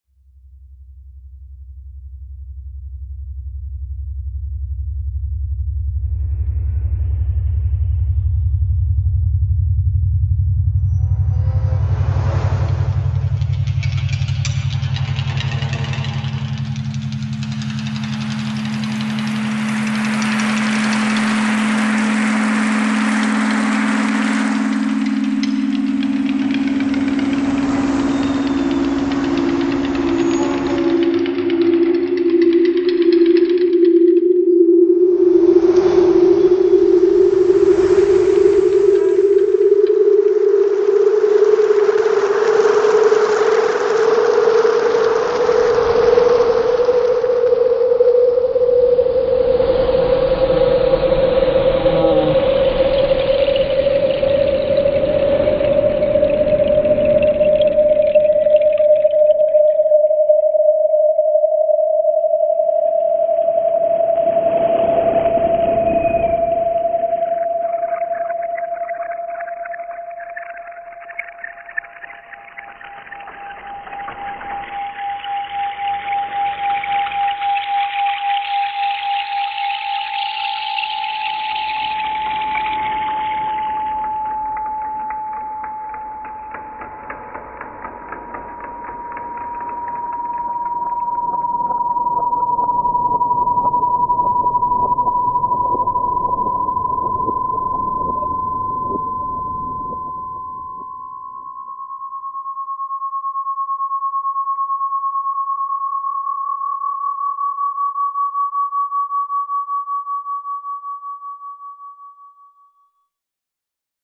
File under: Avantgarde